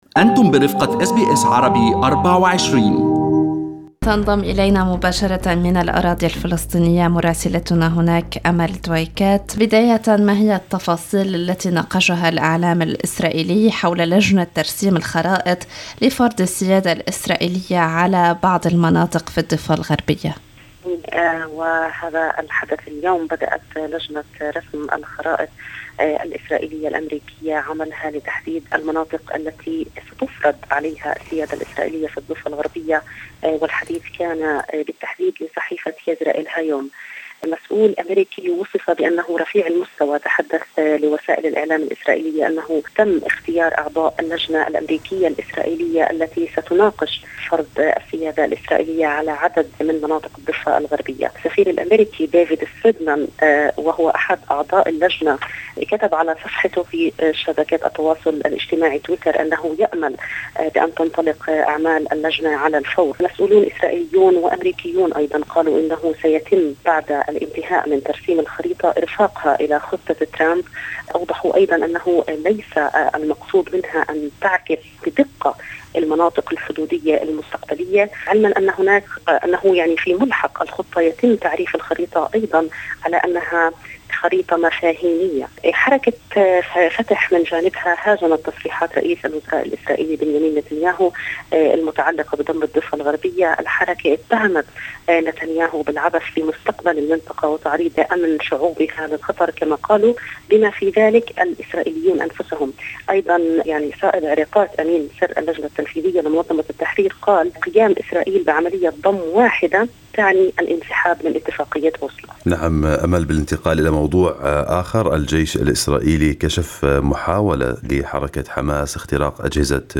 من مراسلينا: أخبار الأراضي الفلسطينية في أسبوع 17/02/2020